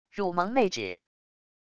乳萌妹纸wav音频